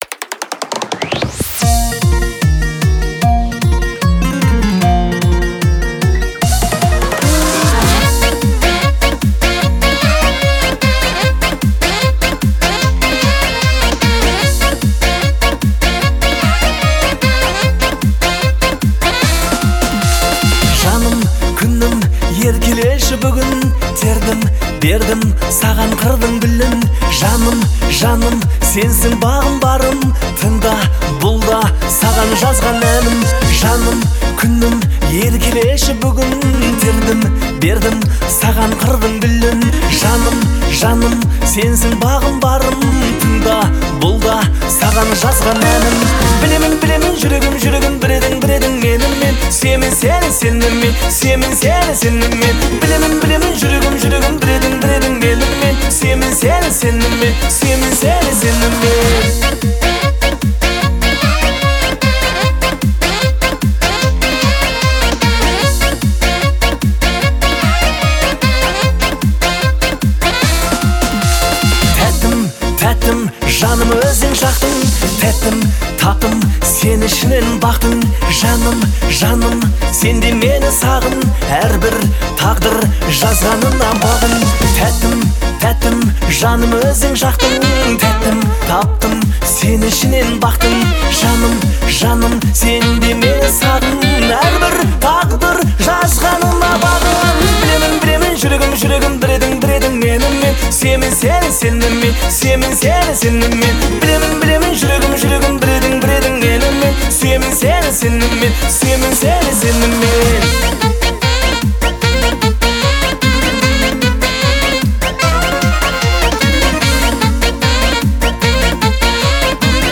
это проникновенная баллада в жанре казахской поп-музыки.